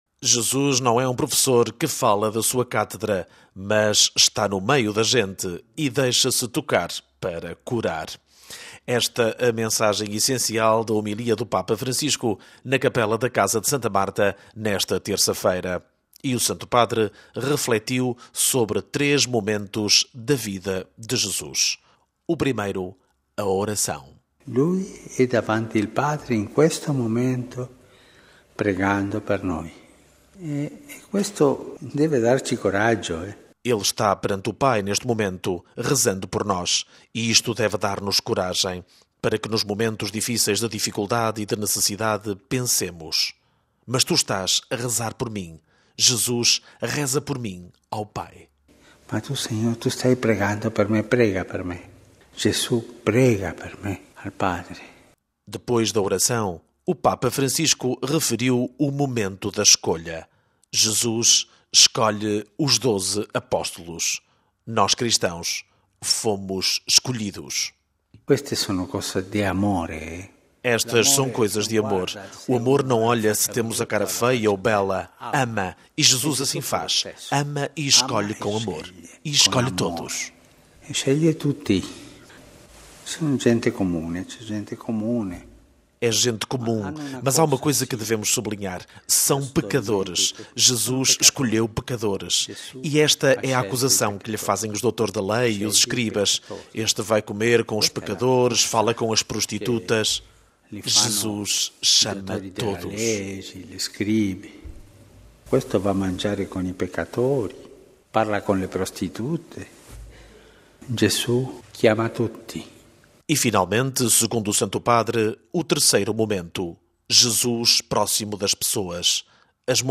Jesus não é um professor que fala da sua cátedra mas está no meio da gente e deixa-se tocar para curar. Esta a mensagem essencial da homilia do Papa Francisco na Capela da Casa de Santa Marta nesta terça-feira.